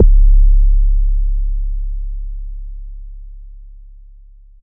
Southside 808 (19).wav